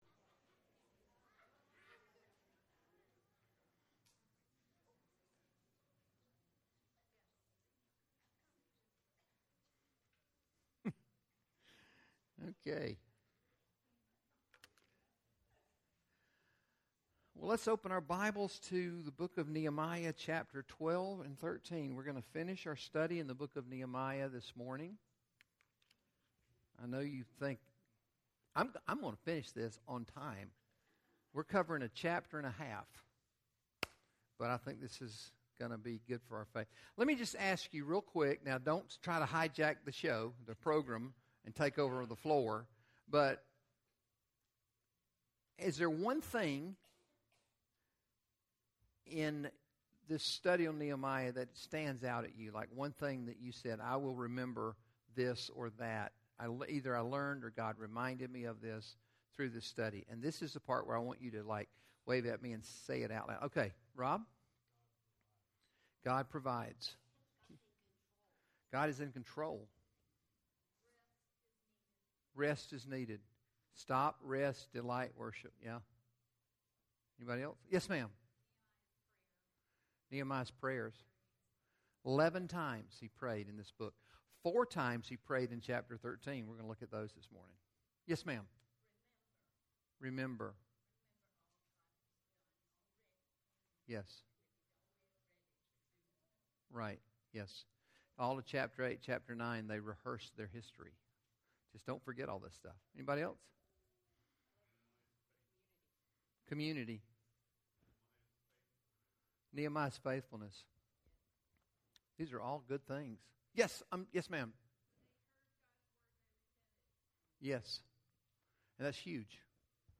Home I'm New About Meet Our Team Contact Us Grow Bible Resources eGroups Ministries Missions Faith Promise Events Connect Give Watch Online Sermons When People You Trust Let You Down, Where Can You Turn?